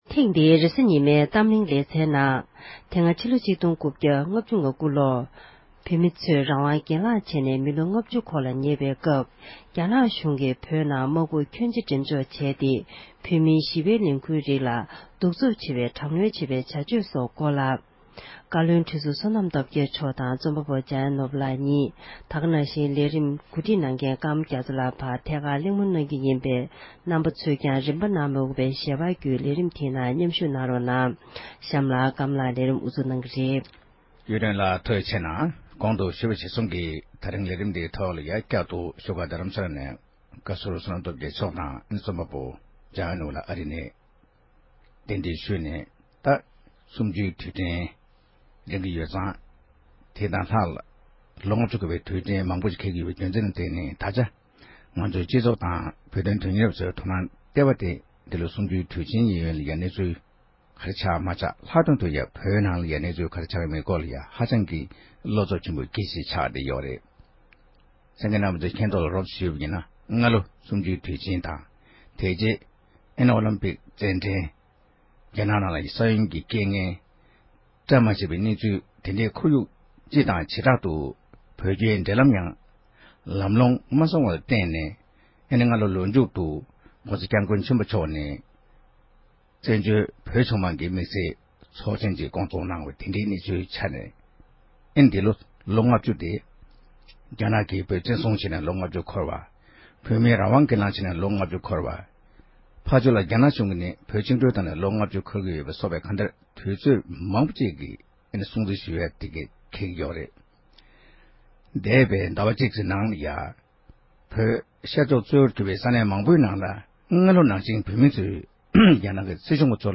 གླེང་མོ་གནང་བ་ཞིག་གསན་རོགས་ཞུ༎